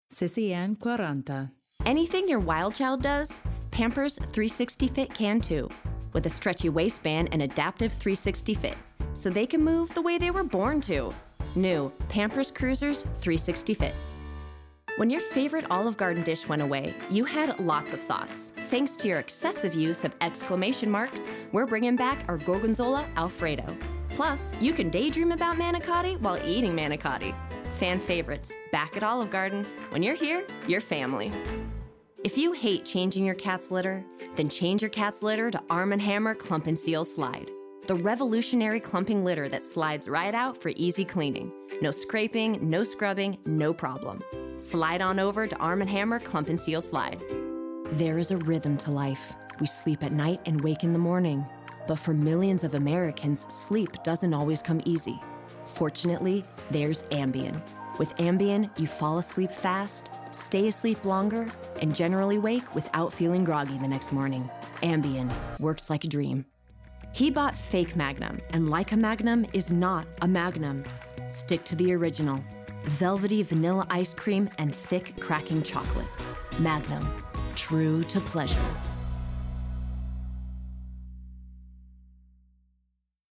Voice Over Reel